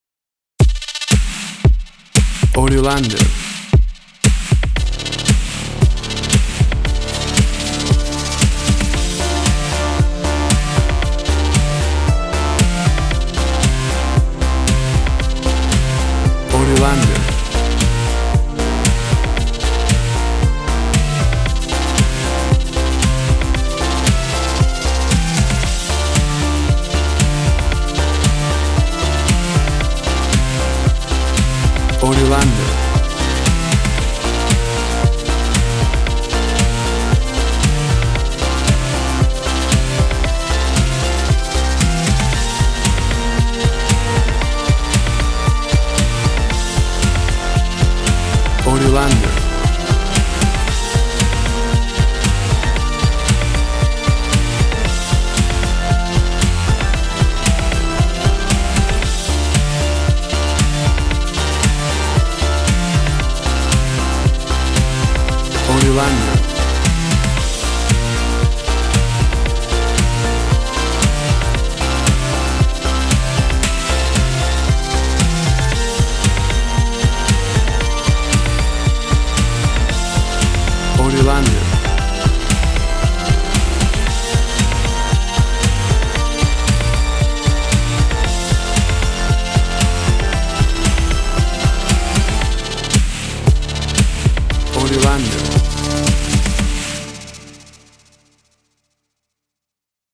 A Synthesizer driven upbeat track.
WAV Sample Rate 24-Bit Stereo, 44.1 kHz
Tempo (BPM) 115